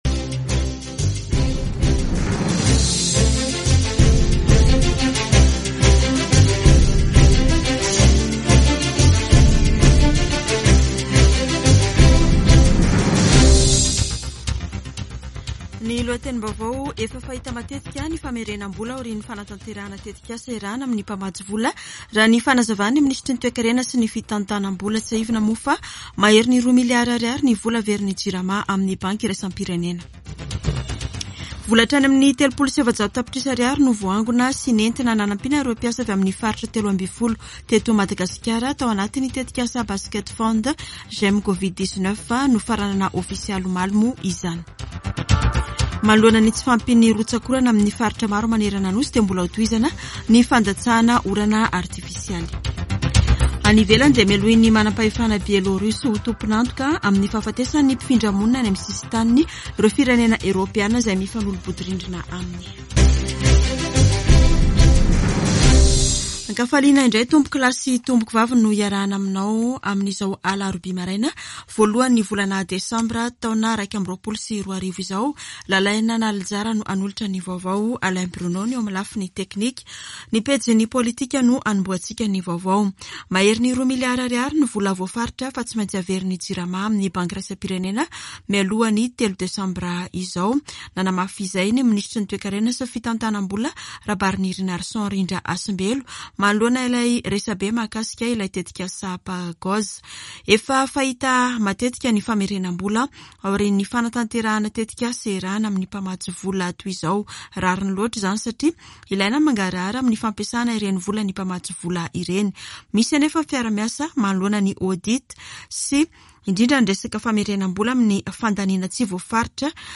[Vaovao maraina] Alarobia 01 desambra 2021